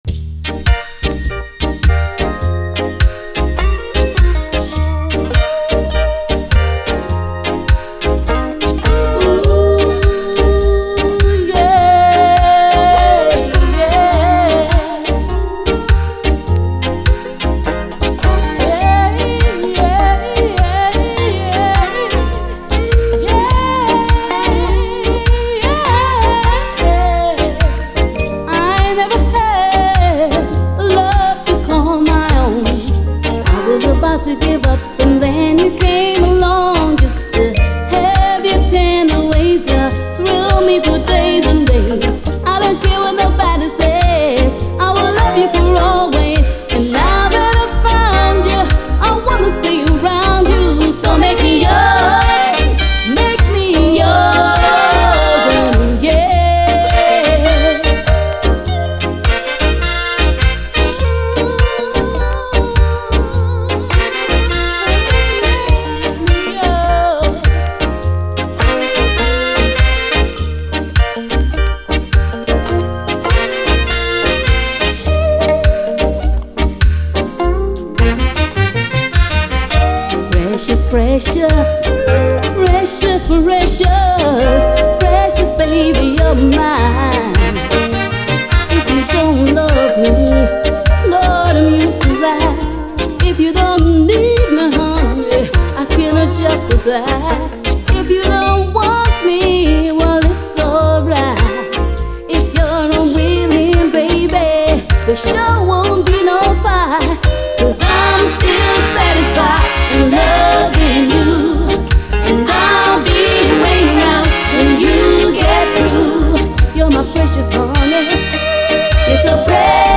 REGGAE
レディー・ソウル・クラシック３曲をメドレー・カヴァーしたソウルフルUKラヴァーズ！